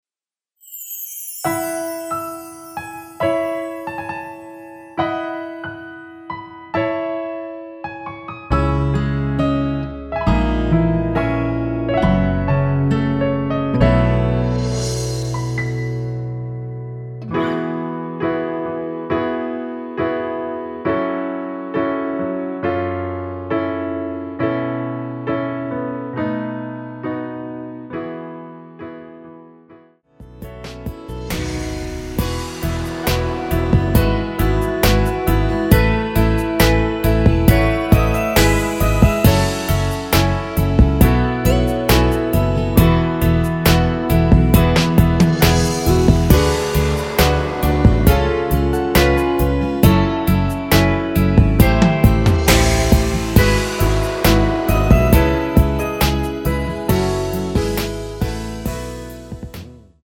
여자키 에서 +2더 높인 MR 입니다.(미리듣기 참조)
앞부분30초, 뒷부분30초씩 편집해서 올려 드리고 있습니다.
중간에 음이 끈어지고 다시 나오는 이유는